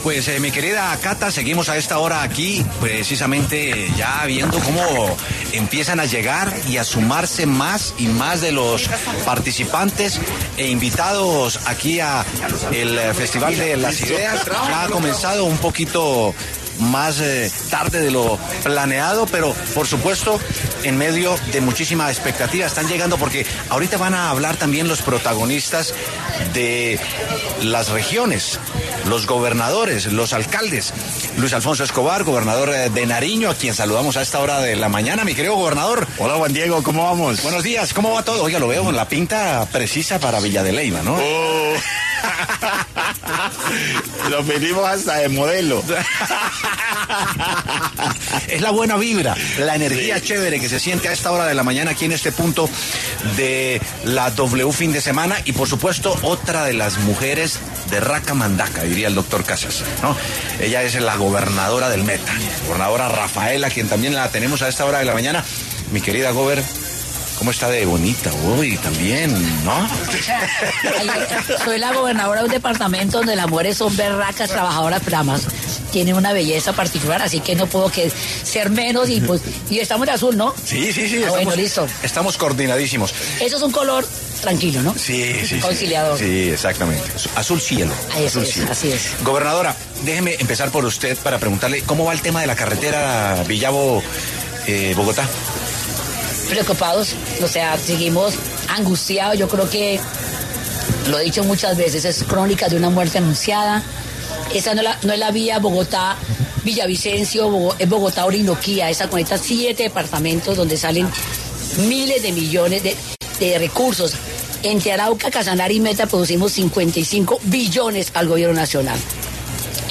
Los gobernadores de Nariño y Meta hablaron con W Fin De Semana desde el Festival de las Ideas.
En medio del Festival de las Ideas que se realizó en Villa de Leyva, los gobernadores Luis Alfonso Escobar, de Nariño, y Rafaela Cortés, del Meta, hicieron una ‘radiografía’ de cómo está la seguridad en sus departamentos, siendo uno de los temas que más aqueja a las regiones.